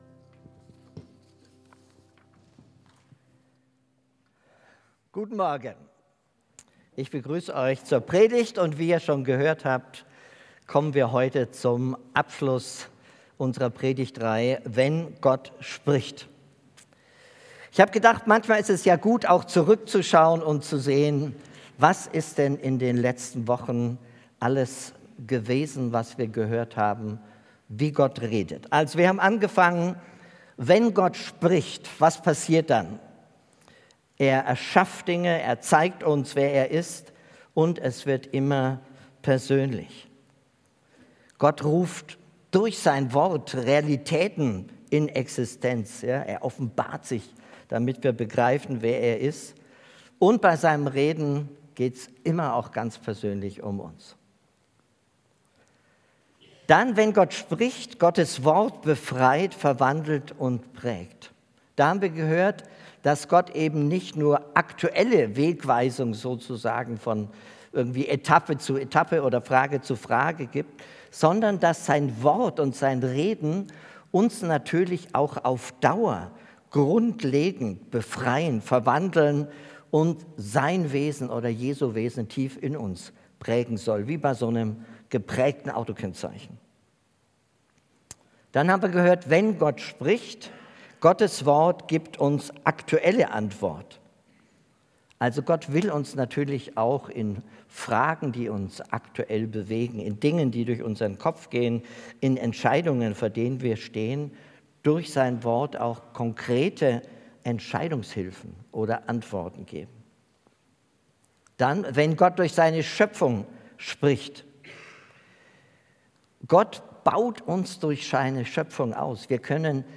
Predigt Wenn Gott spricht... und wir hören und handeln?!